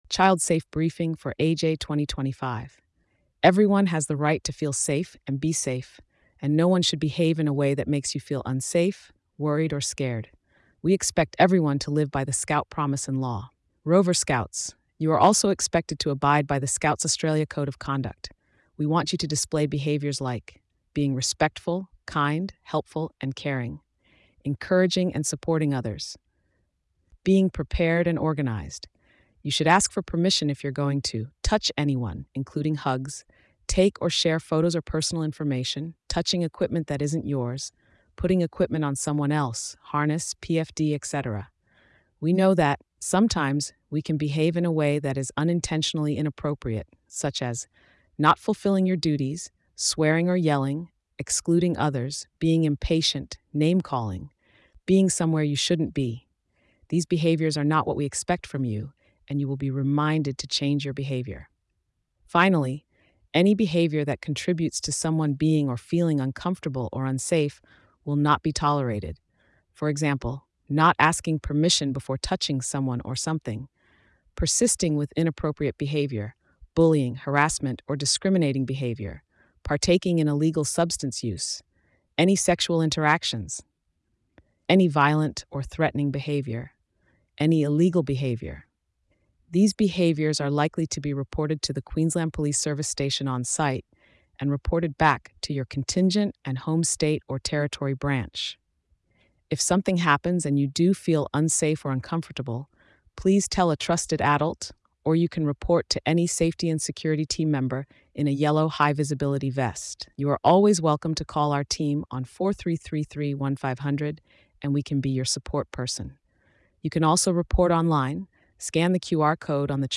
Child Safety Briefing - Youth.mp3